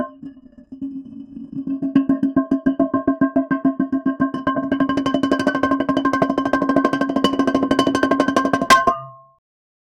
No background noise, no other people, no reverb tail besides natural room echo." 0:10 hammer auf amboss 0:10 midium heavy Steel barrel rolling on stones loop 0:10
midium-heavy-steel-barrel-bqjzsq3n.wav